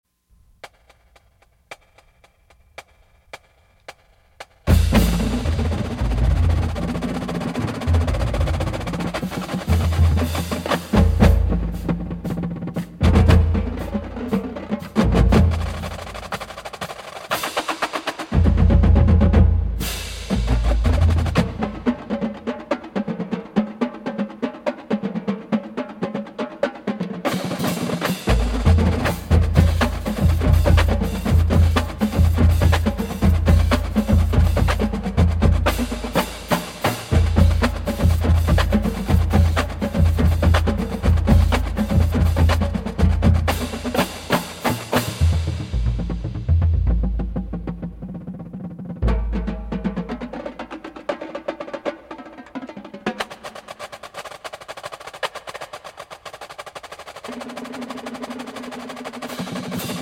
Drumline Cadence24.Traditional: Drumline Cadence   1:38